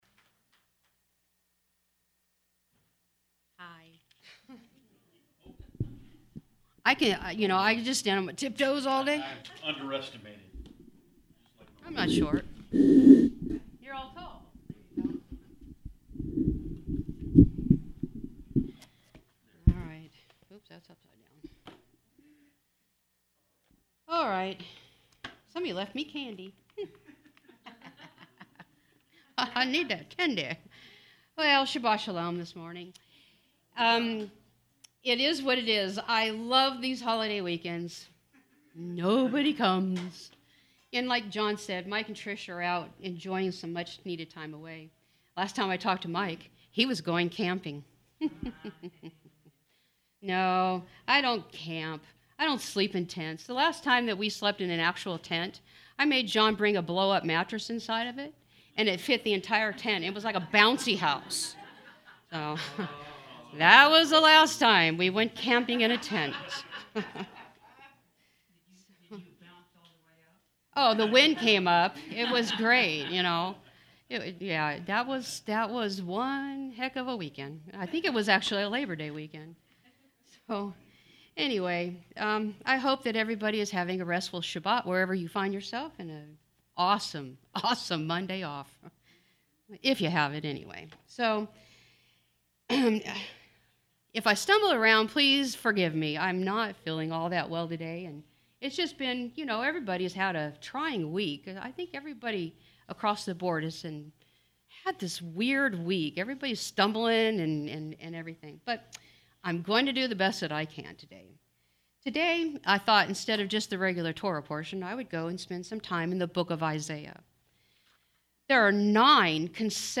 Hebraic Roots Audio sermon